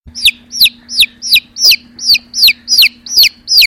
Чириканье птенца